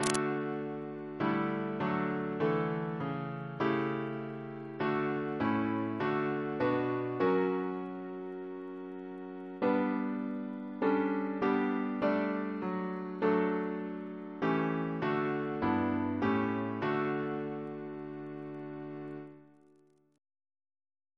Double chant in D Composer: Walter Parratt (1841-1924) Reference psalters: ACB: 159